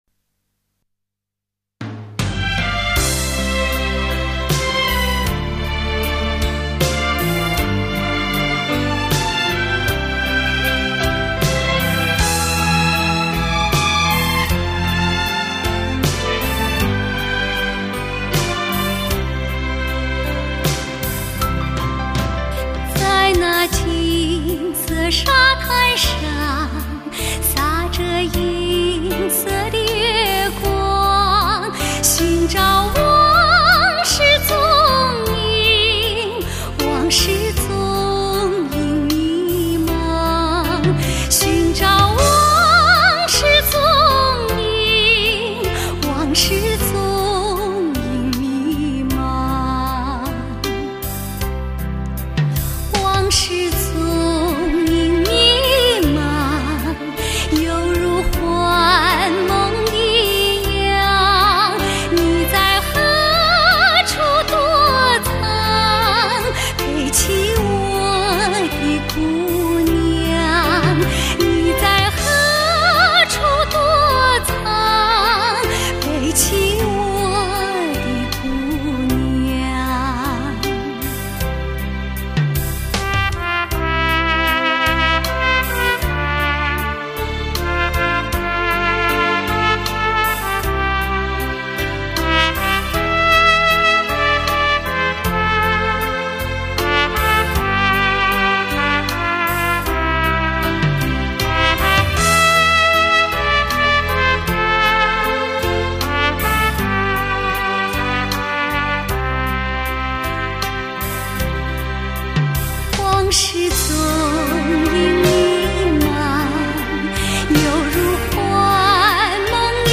经典民谣
演绎天籁般的合唱，乡村民谣节奏元素，爵士、布鲁斯特色、拉丁、华尔兹等丰韵独特创新风格